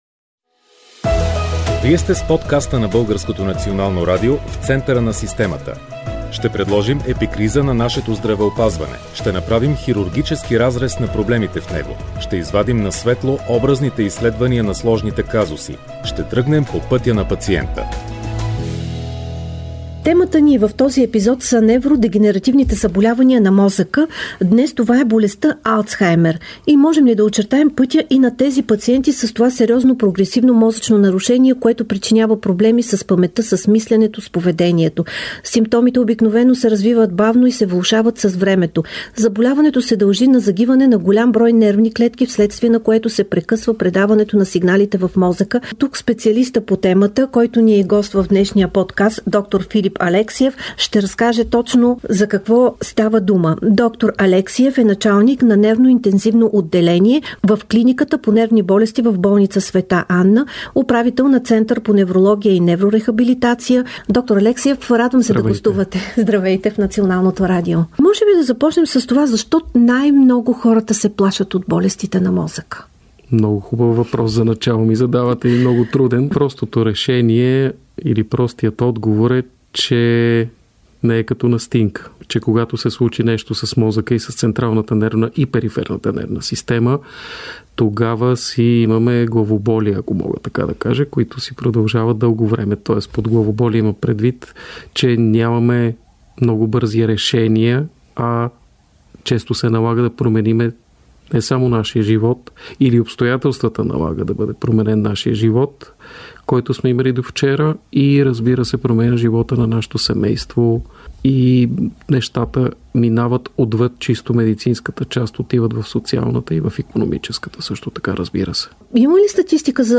От разговора ще научим